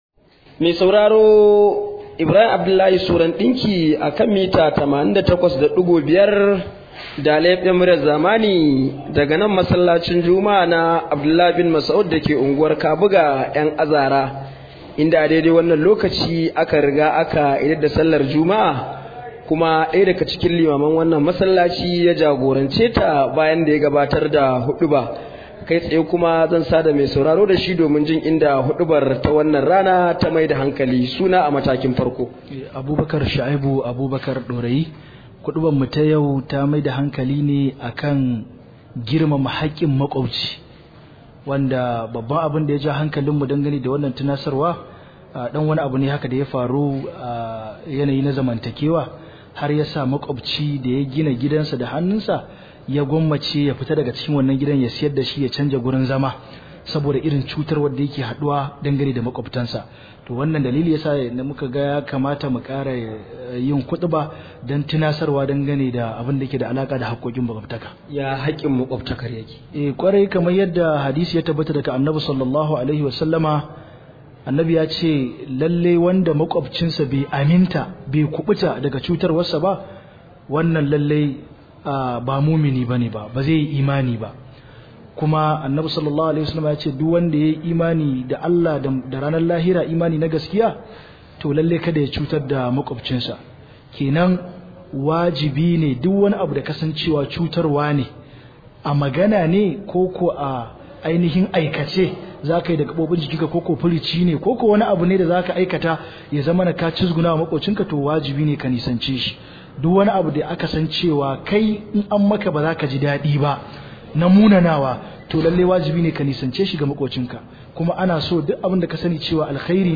Rahoton huduba: Mu guji cutar da makwabtan mu – Limami